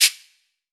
kits/Cardiak/Percs/TC2 Perc2.wav at 32ed3054e8f0d31248a29e788f53465e3ccbe498
TC2 Perc2.wav